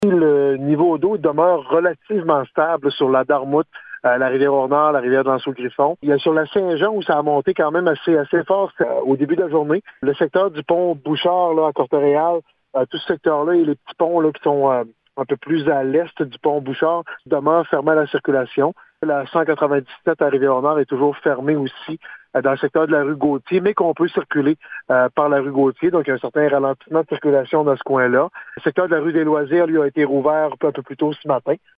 Le maire Daniel Côté résume la situation :